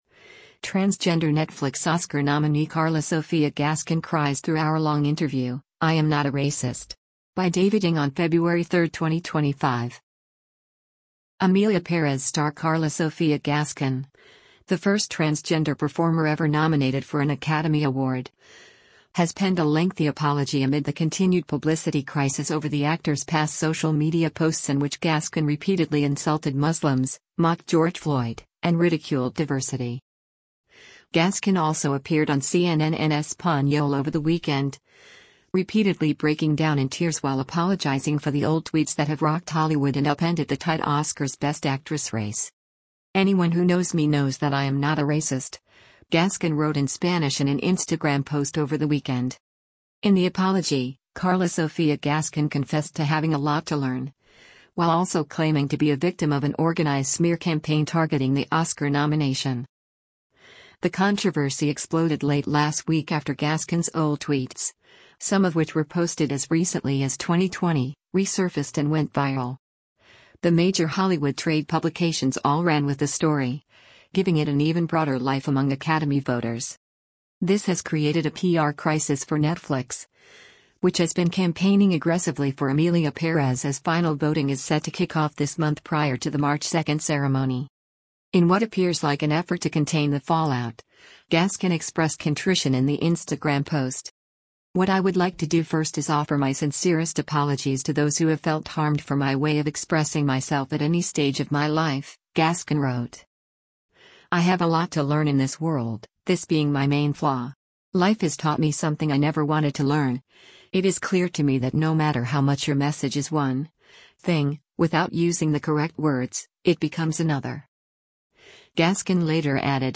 Gascón also appeared on CNN en Español over the weekend, repeatedly breaking down in tears while apologizing for the old tweets that have rocked Hollywood and upended the tight Oscars Best Actress race.